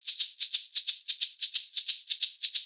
Index of /90_sSampleCDs/USB Soundscan vol.36 - Percussion Loops [AKAI] 1CD/Partition A/10-90SHAKERS